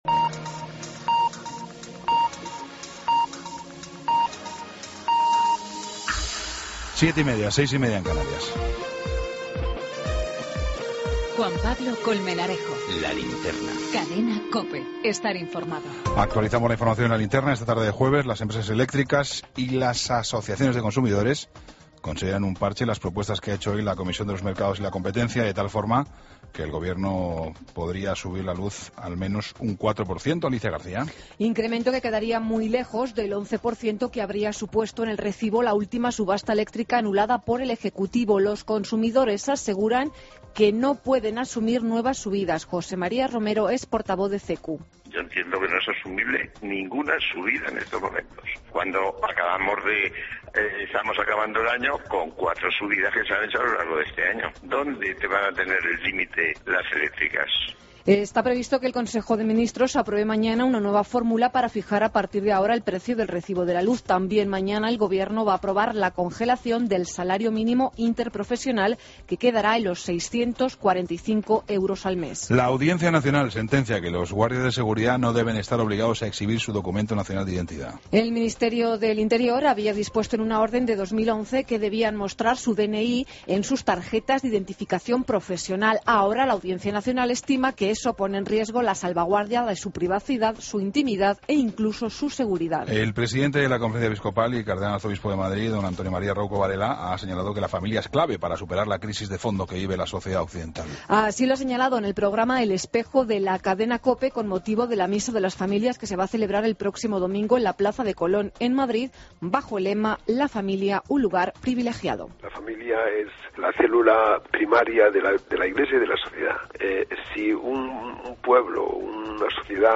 AUDIO: Toda la información con Juan Pablo Colmenarejo.
Entrevista